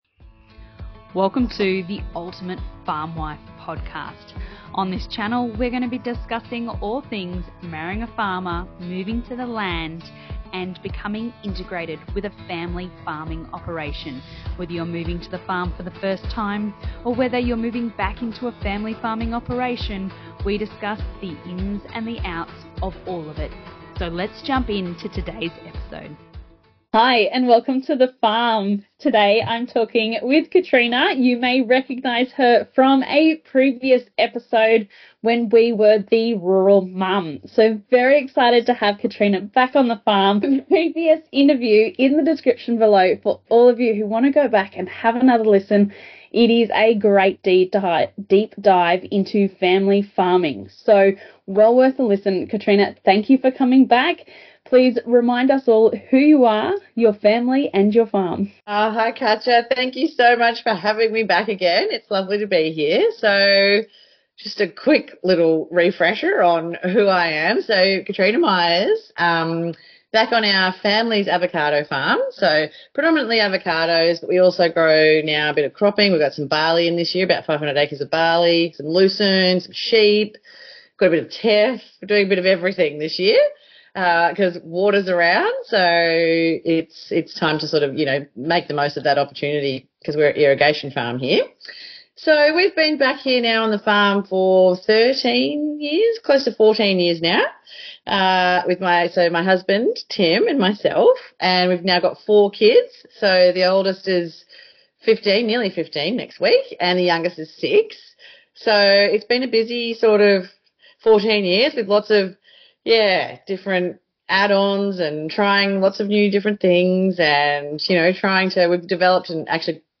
Don’t miss this inspiring conversation about creating new opportunities in farming!